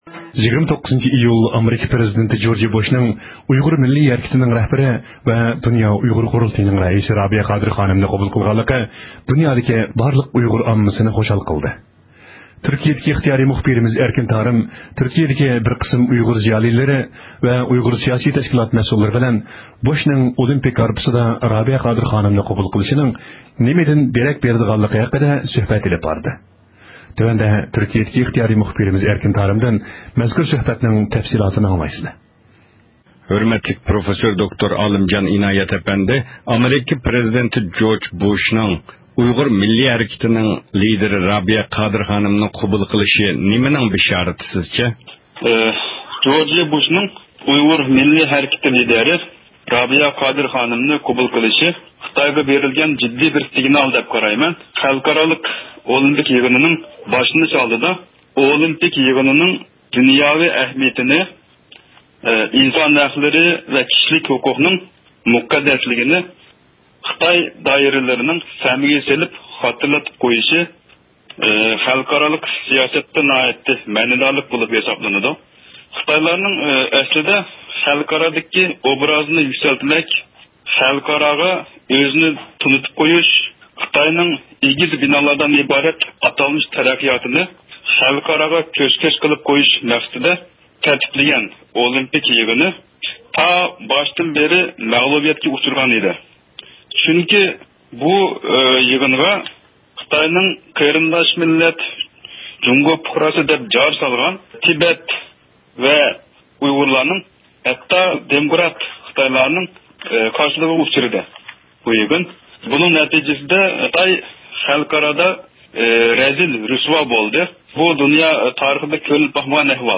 بۇ ھەقتە مەلۇمات ئېلىش ئۈچۈن تۈركىيىدىكى ئۇيغۇرلار بىلەن سۆھبەت ئېلىپ باردۇق.